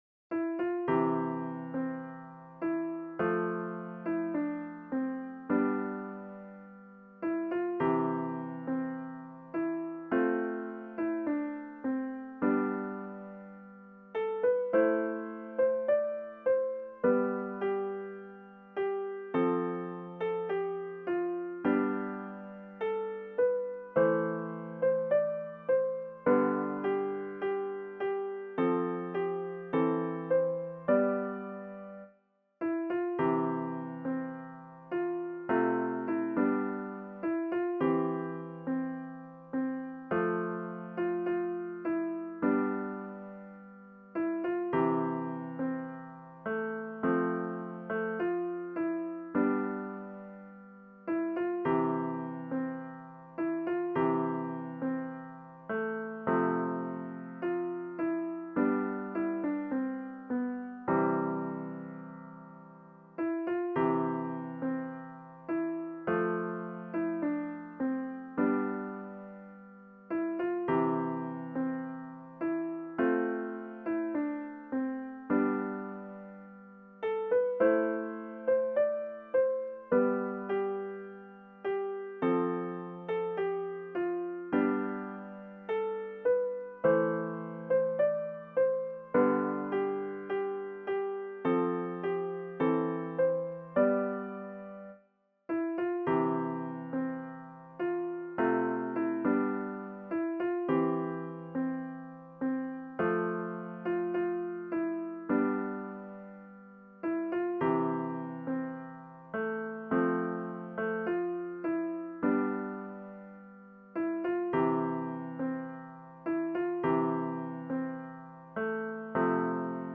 Voicing/Instrumentation: Primary Children/Primary Solo
Vocal Solo Medium Voice/Low Voice